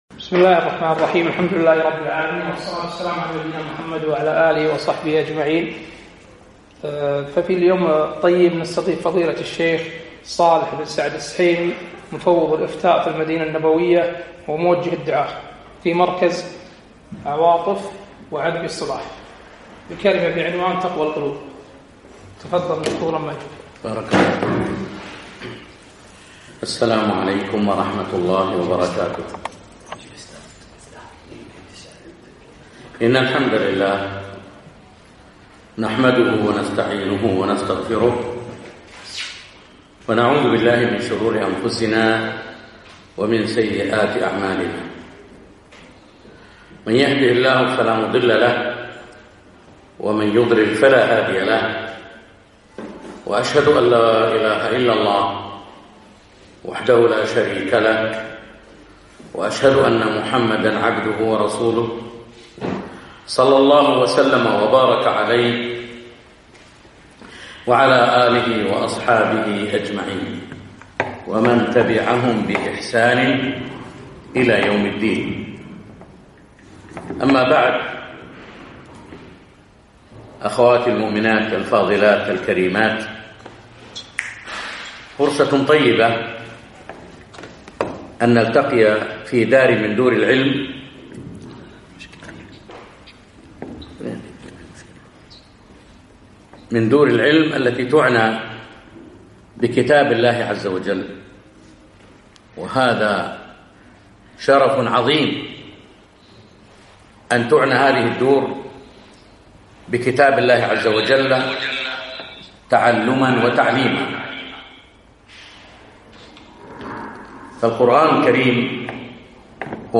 محاضرة - ( تقوى القلوب )